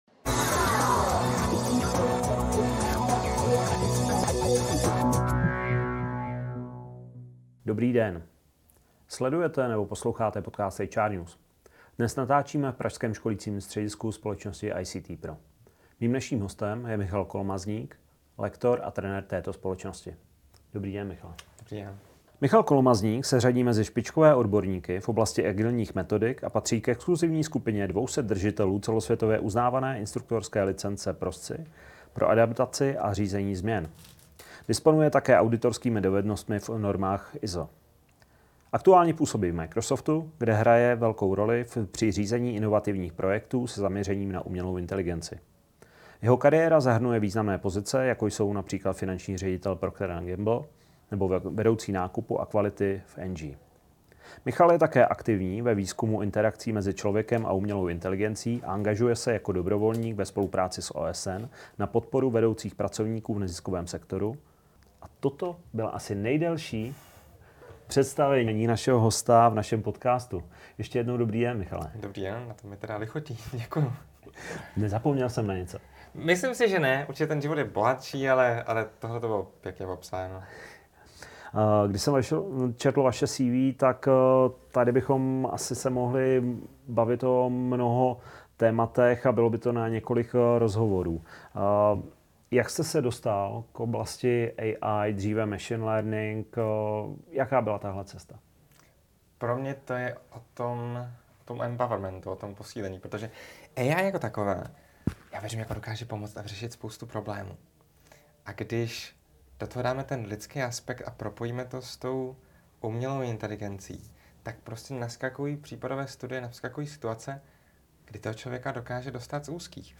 V podcastu se dozvíte: Rozhovor se zaměřuje na umělou inteligenci a její využití.